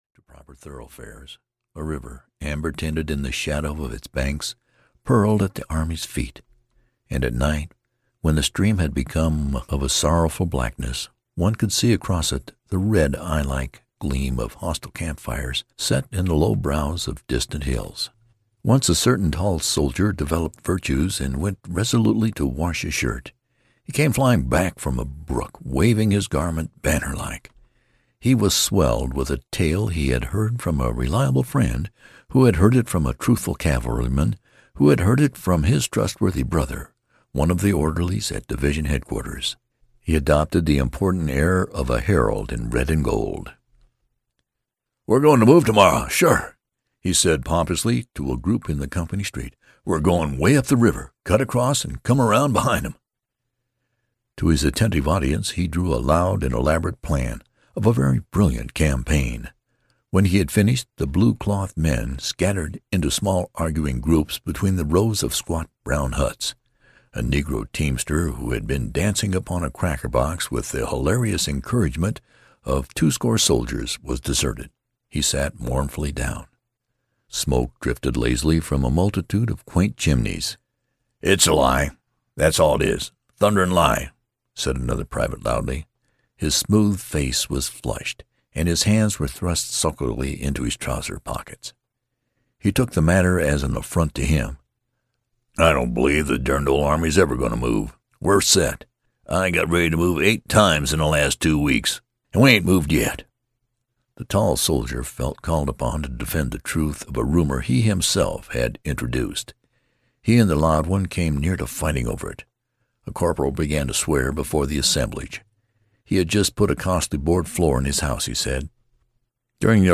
The Red Badge of Courage (EN) audiokniha
Ukázka z knihy